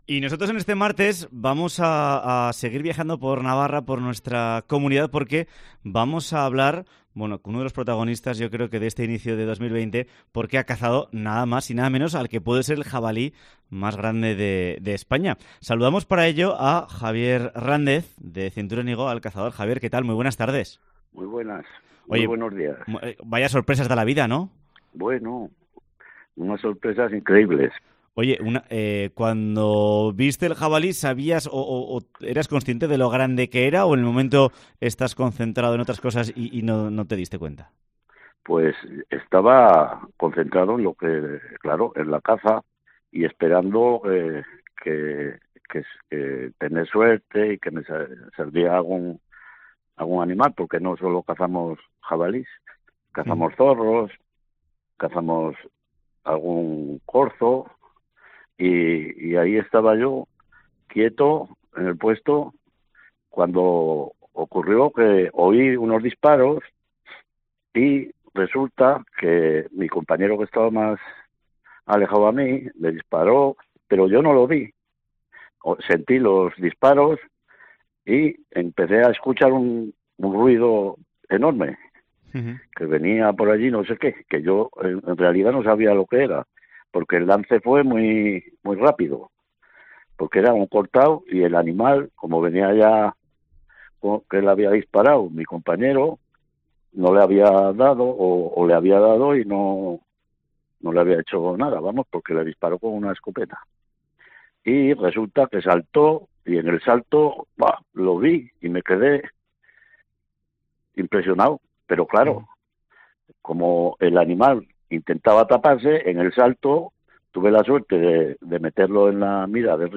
Programa de actualidad y entretenimiento que repasa la actualidad de la Comunidad Foral de Navarra.